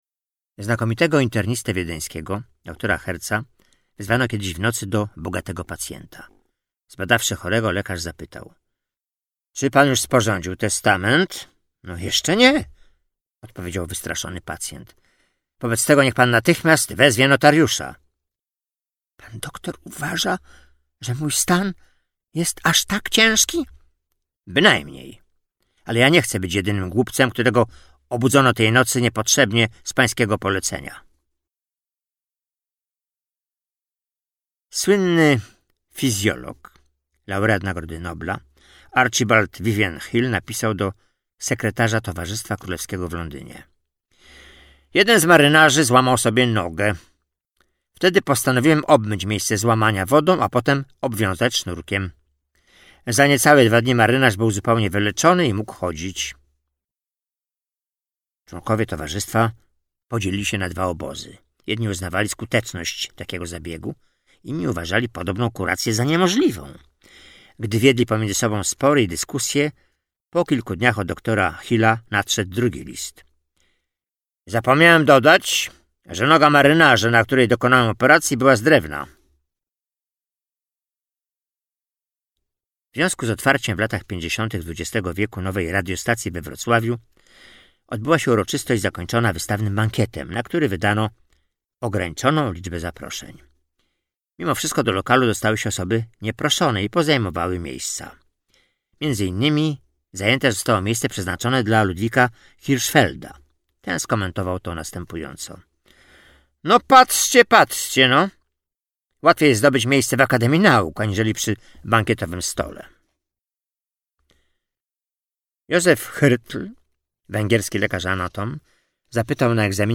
Najlepsze anegdoty o lekarzach - Audiobook mp3
Lektor